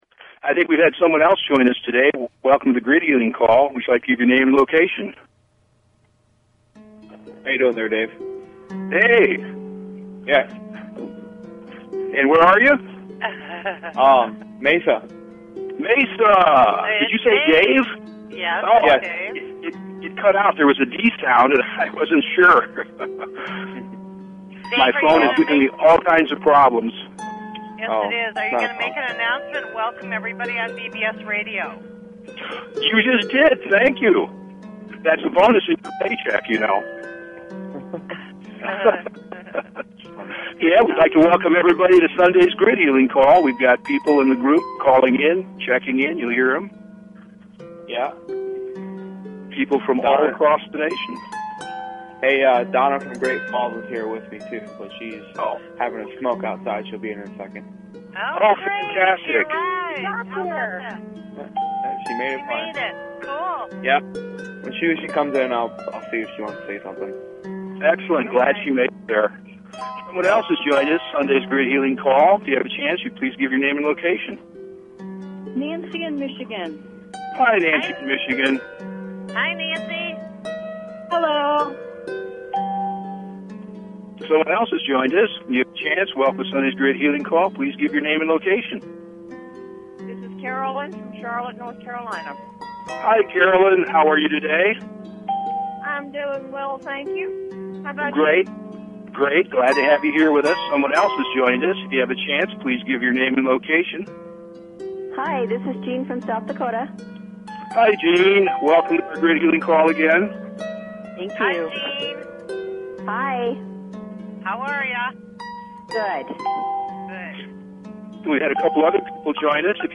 Talk Show Episode, Audio Podcast, Personal_Planetary_Healing_Meditation and Courtesy of BBS Radio on , show guests , about , categorized as
Personal Planetary Healing Meditation